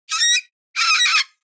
دانلود آهنگ جنگل 27 از افکت صوتی طبیعت و محیط
دانلود صدای جنگل 27 از ساعد نیوز با لینک مستقیم و کیفیت بالا
جلوه های صوتی